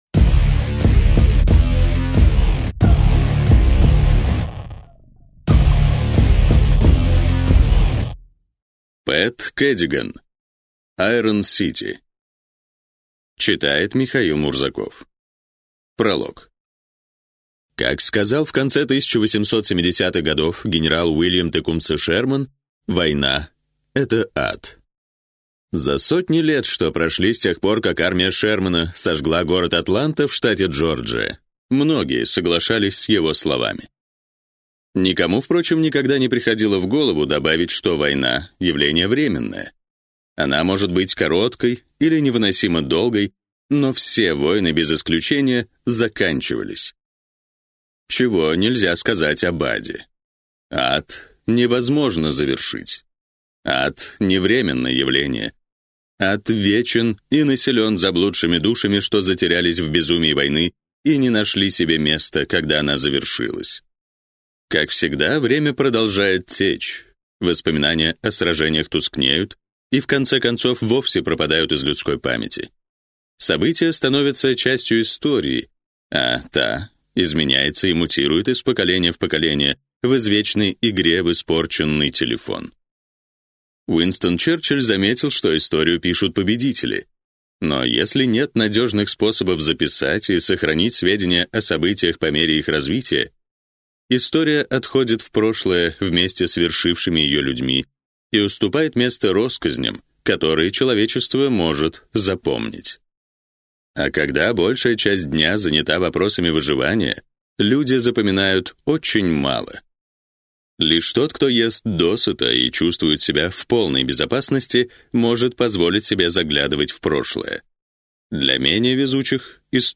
Аудиокнига Алита: Боевой ангел. Айрон сити | Библиотека аудиокниг